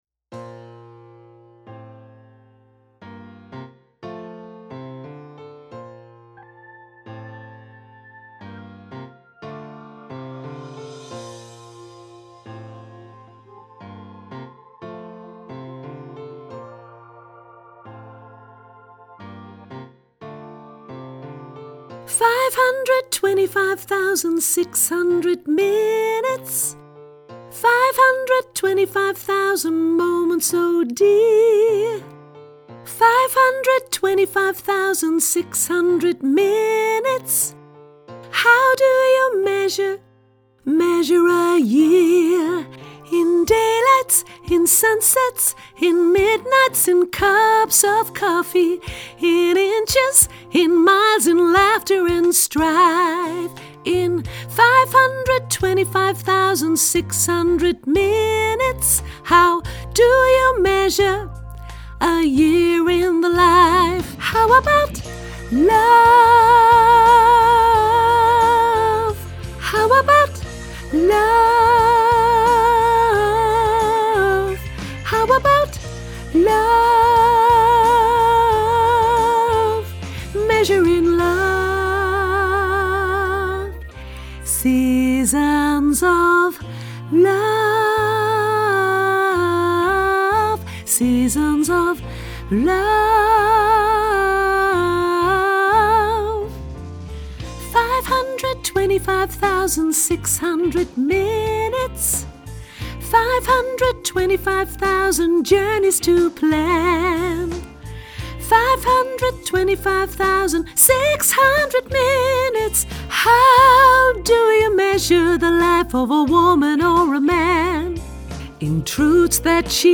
sopraan mezzo